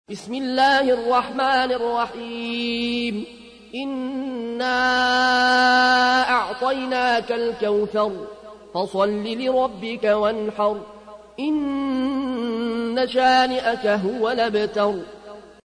تحميل : 108. سورة الكوثر / القارئ العيون الكوشي / القرآن الكريم / موقع يا حسين